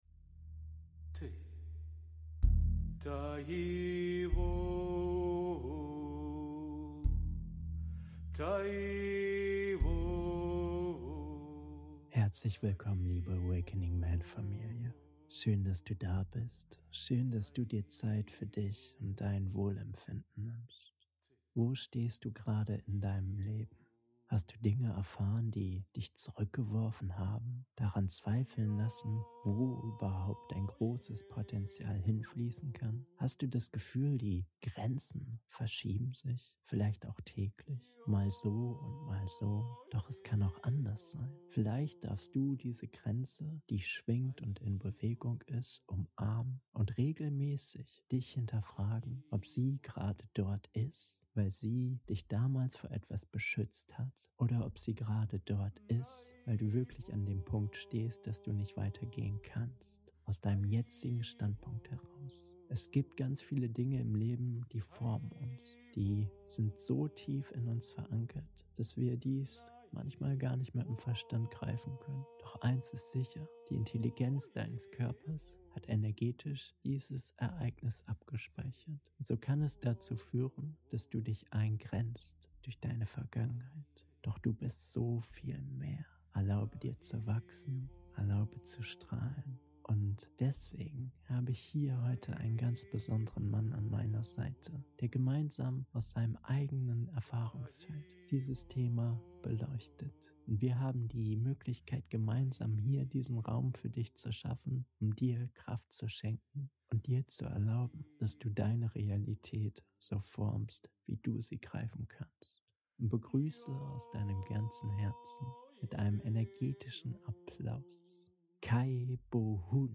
Der Körper vergisst nichts - Interview